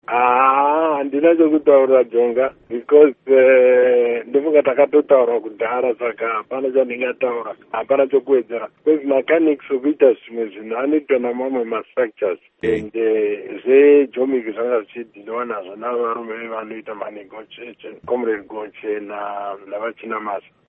Embed share Hurukuro naVaRugare Gumbo by VOA Embed share The code has been copied to your clipboard.